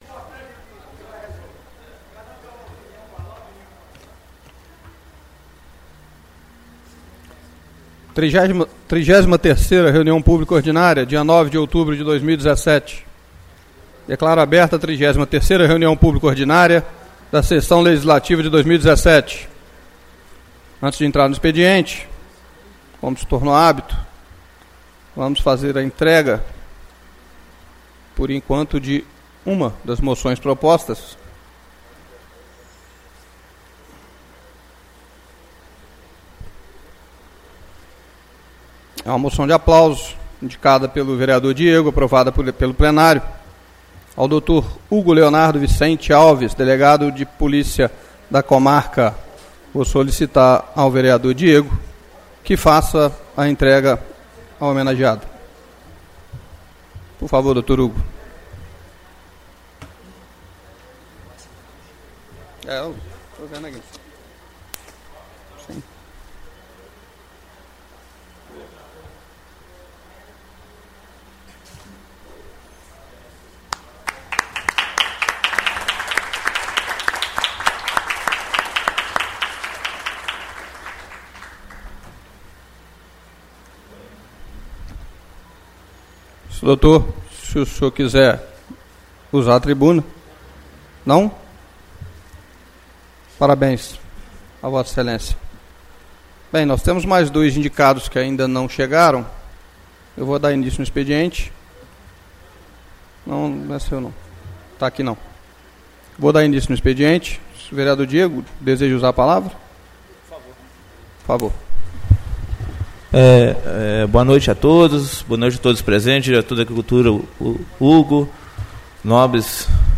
33° Reunião Pública Ordinária 09/10/2017